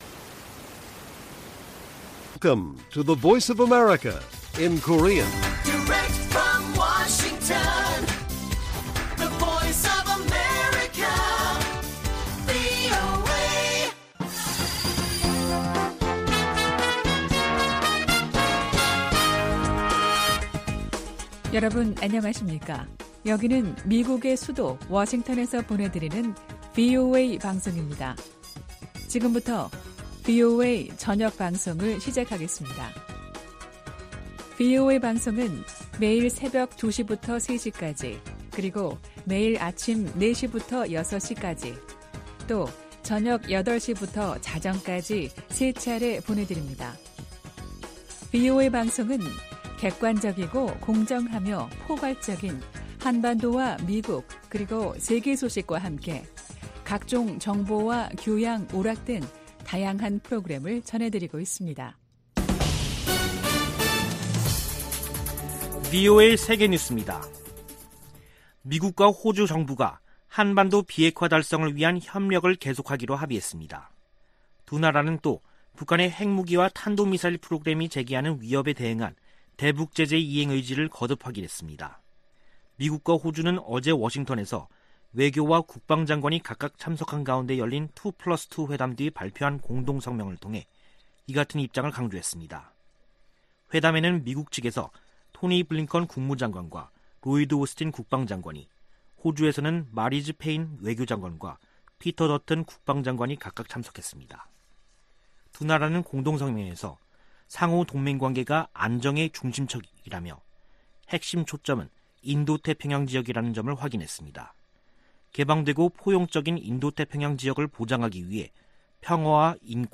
VOA 한국어 간판 뉴스 프로그램 '뉴스 투데이', 2021년 9월 17일 1부 방송입니다. 북한이 영변 우라늄 농축 시설을 확장하는 정황이 담긴 위성사진이 공개됐습니다. 76차 유엔총회가 14일 개막된 가운데 조 바이든 미국 대통령 등 주요 정상들이 어떤 대북 메시지를 내놓을지 주목됩니다. 유럽연합(EU)은 올해도 북한 인권 규탄 결의안을 유엔총회 제3위원회에 제출할 것으로 알려졌습니다.